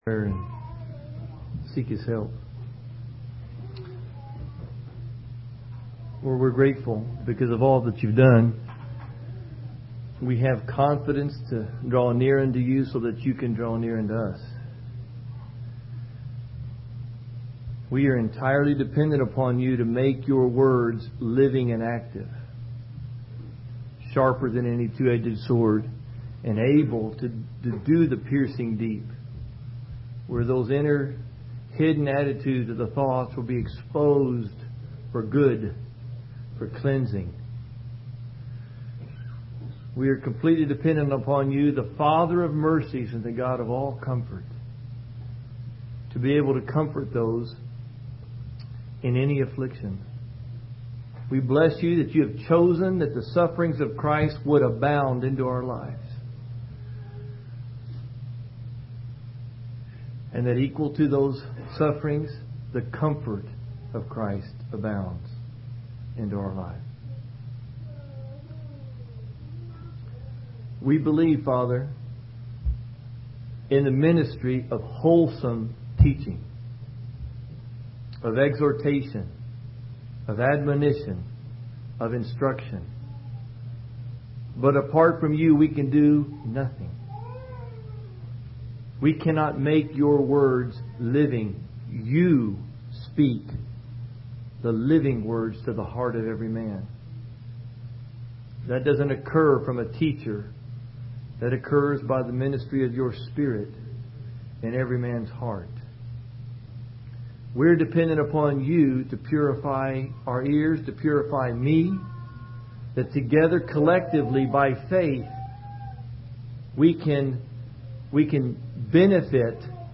In this sermon, the preacher emphasizes the importance of having a clear vision of Jesus in order to avoid perishing and living a purposeless life. The story of Mary and Martha in Luke chapter 10 is used as an example of how distractions and worldly concerns can hinder our vision of Christ. The preacher urges the audience to lay aside anything that belongs to the sinful nature and to run in the Spirit.